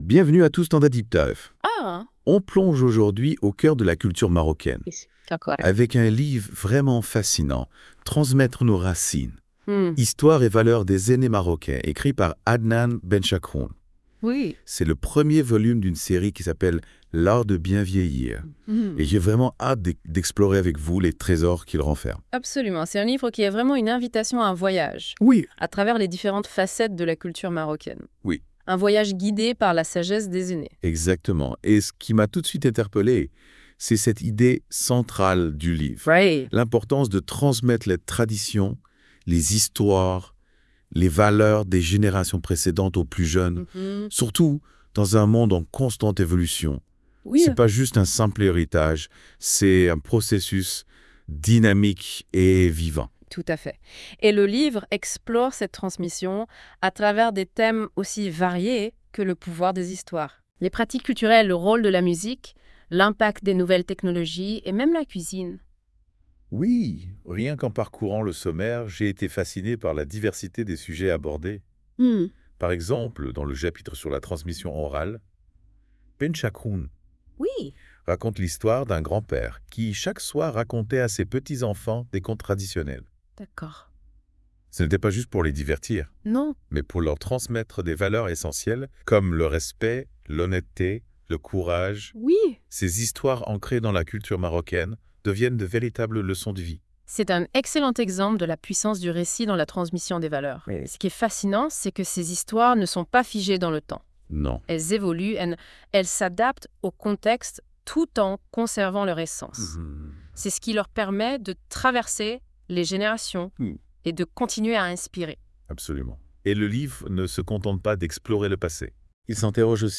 Débat sur le livre (45.33 Mo) Comment le livre "Transmettre nos racines" aborde-t-il la transmission intergénérationnelle ?